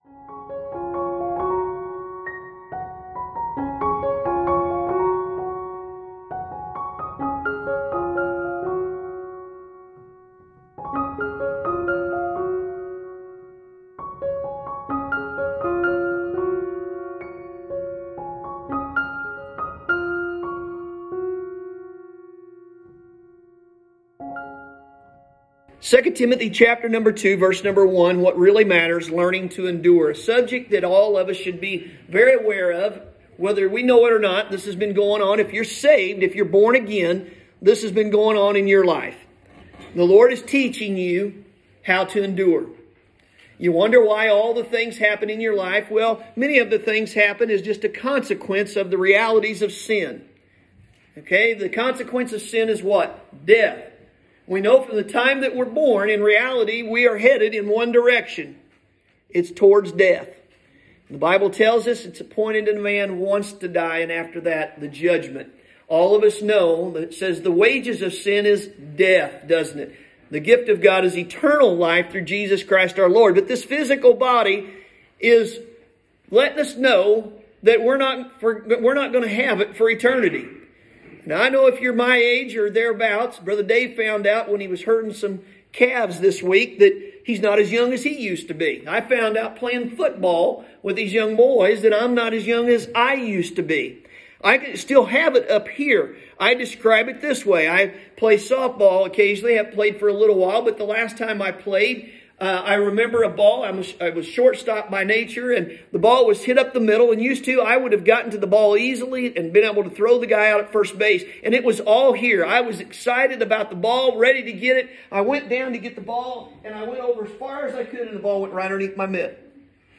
Sermon – Learning To Endure